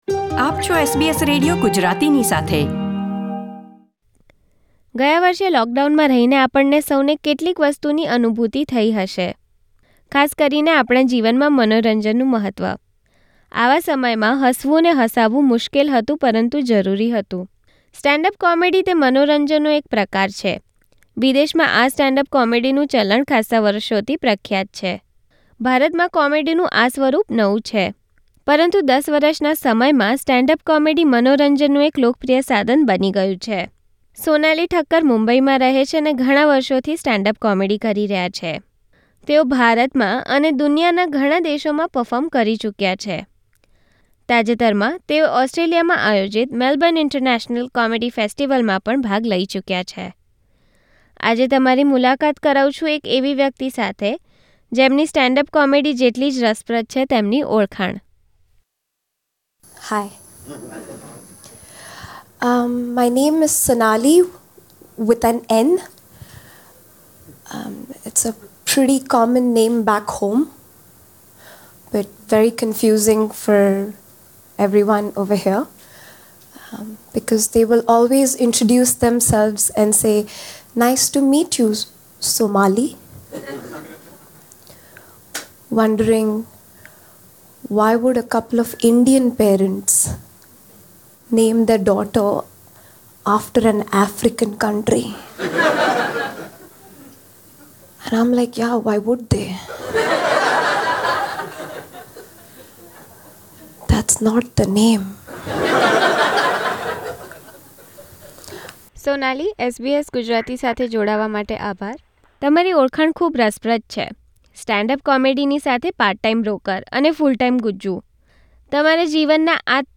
Listen to news and interviews in Gujarati: Follow SBS Gujarati on Apple Podcasts, Google Podcasts and Spotify Share